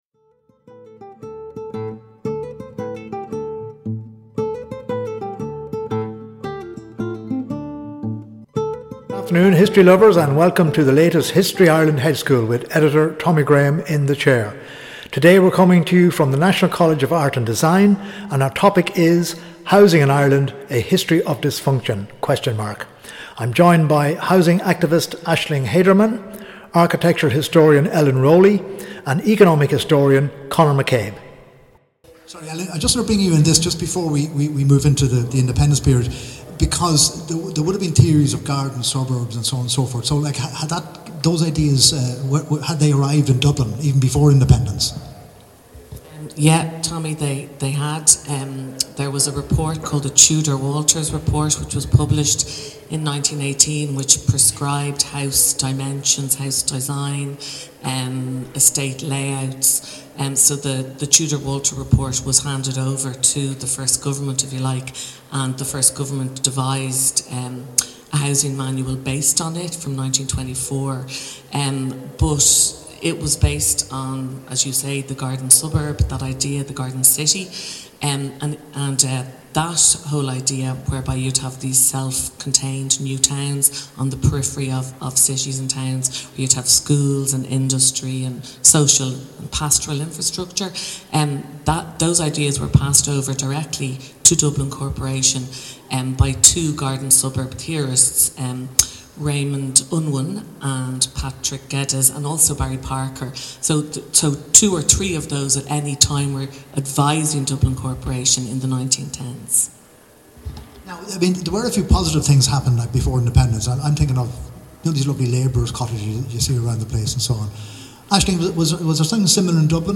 (Recorded live on Monday 9 June 2025 @ the National College of Art and Design)